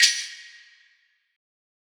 Metro Big Perc.wav